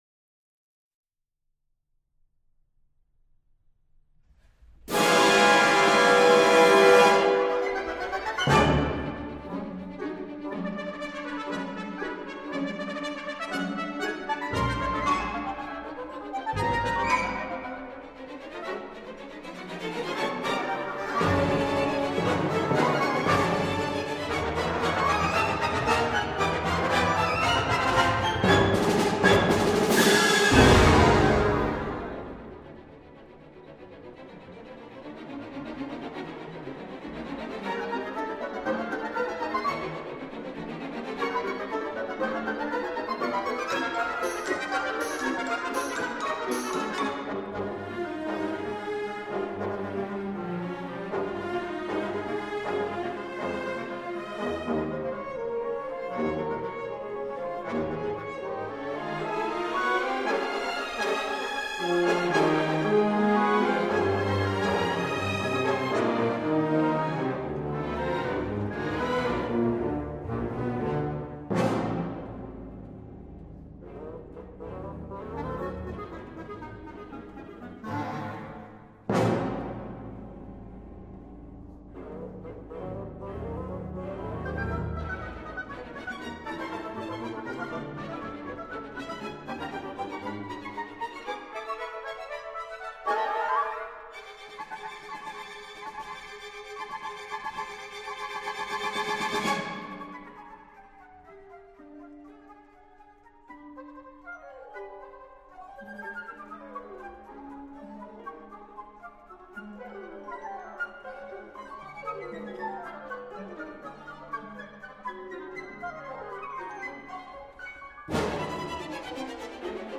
Dans cette partition plutôt joyeuse et que j’apprécie vraiment beaucoup, les trois solistes se partagent la partie concertante, et la partie de piano est la plus facile, ayant été pensé, vraisemblablement, pour l’un des jeunes élèves du compositeur, encore peu assuré. Le triple concerto bénéficie d’une belle discographie, et l’on peut, selon son humeur, privilégier une approche chambriste comme dans la version de ce jour, ou une vision plus large où l’orchestre, accompagnant des solistes de grand renom, prend plus de place.